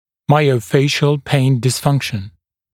[ˌmaɪəu’feɪʃl peɪn dɪs’fʌŋkʃn][ˌмайоу’фэйшл пэйн дис’фанкшн]миофициальная болевая дисфункция